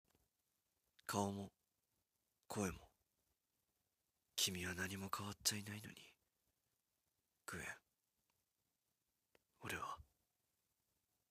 🌿 しっとりセリフバージョン